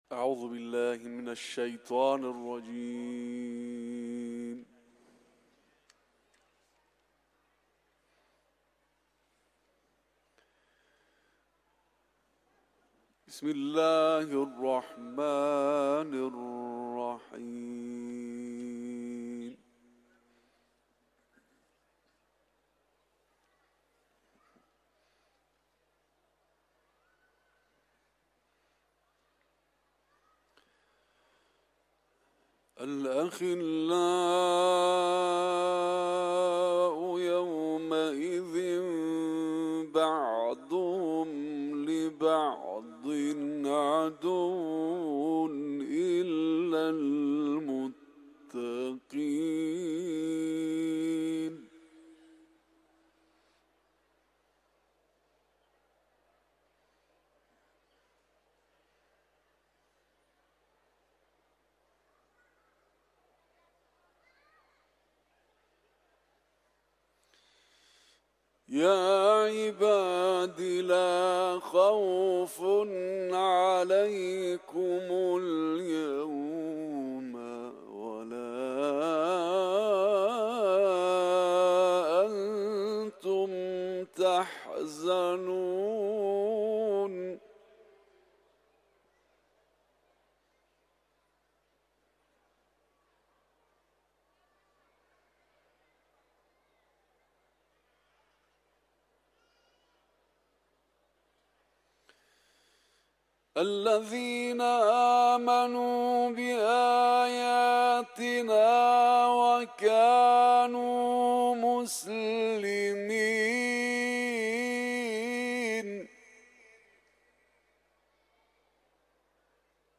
Kuran tilaveti ، Zuhruf suresi